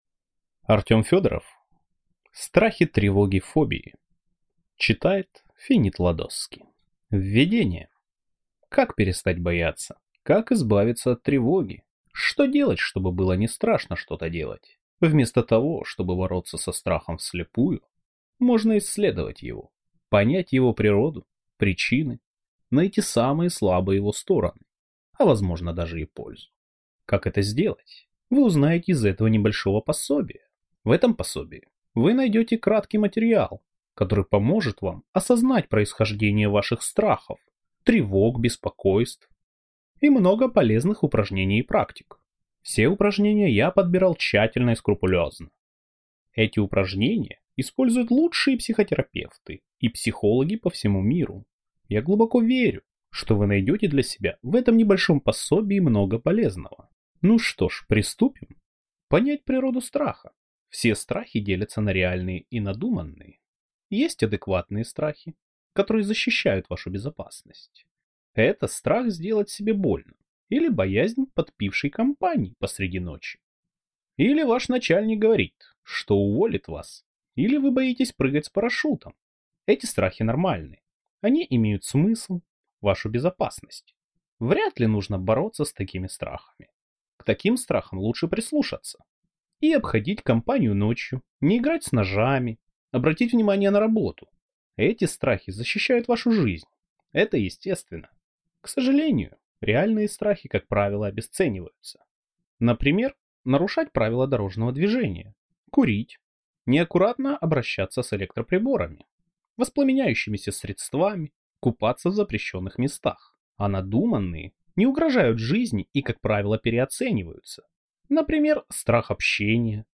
Аудиокнига Страхи. Тревоги. Фобии | Библиотека аудиокниг